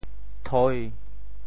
“釵”字用潮州话怎么说？
钗（釵） 部首拼音 部首 釒 总笔划 11 部外笔划 3 普通话 chāi 潮州发音 潮州 toi1 文 中文解释 钗 <名> (形声。